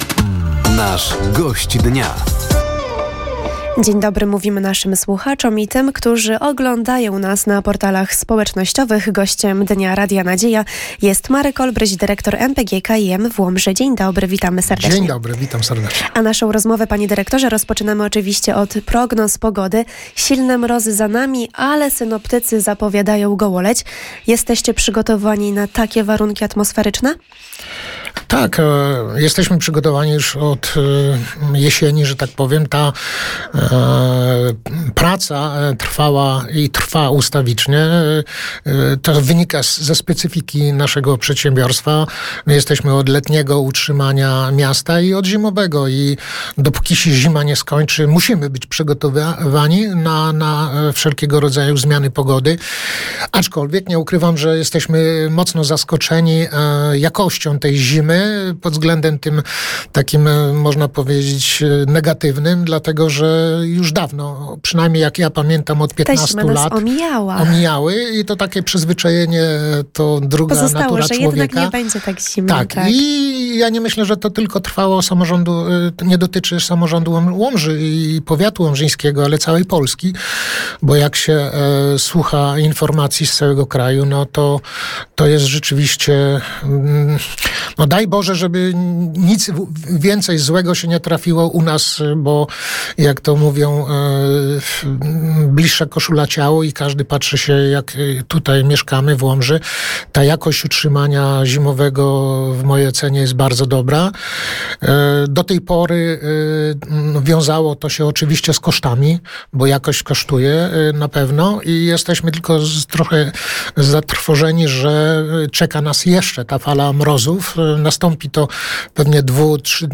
Przygotowania drogowców do nadchodzących trudnych warunków atmosferycznych, sezon zimowy i związane z nim prace, a także nadchodzące inwestycje – to główne tematy rozmowy z Gościem Dnia Radia Nadzieja.